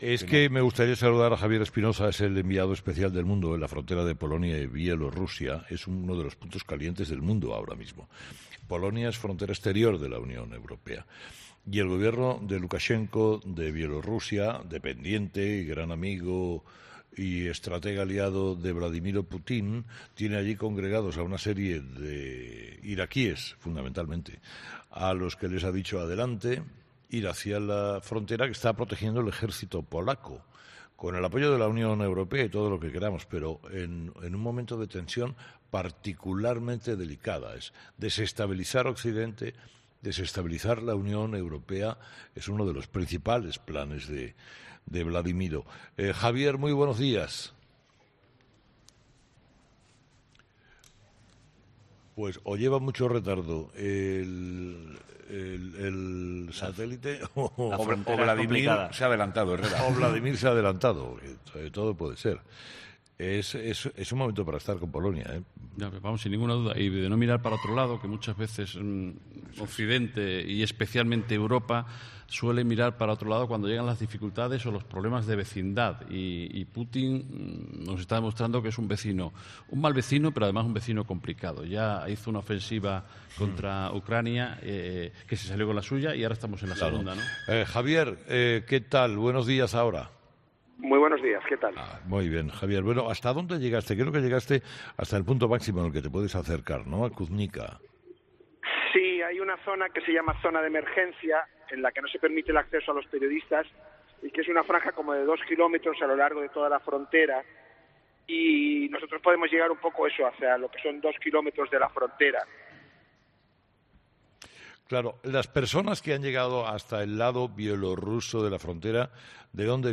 El periodista ha relatado en Herrera en COPE lo que está ocurriendo en el lugar: "la mayoría de personas son iraquíes y sirios", todos ellos personas que son utilizadas para "desestabilizar la Unión Europea".